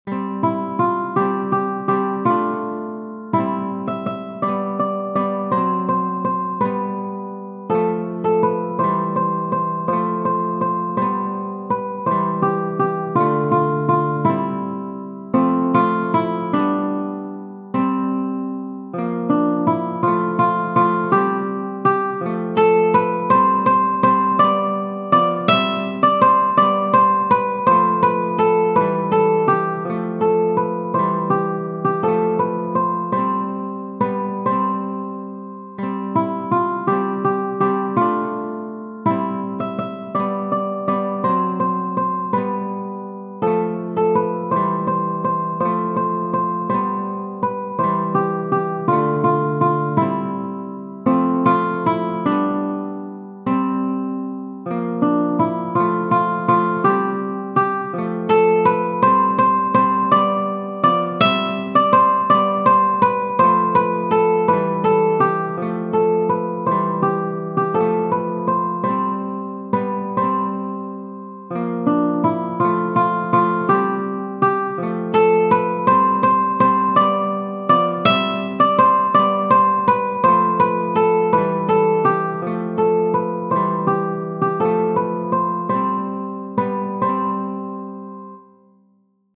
Merula, T. Genere: Rinascimento Testo di Pio di Savoia (?) Folle è ben che si crede che per dolce lusinghe amorose o per fiere minaccie sdegnose dal bel Idolo mio rittraga il piede.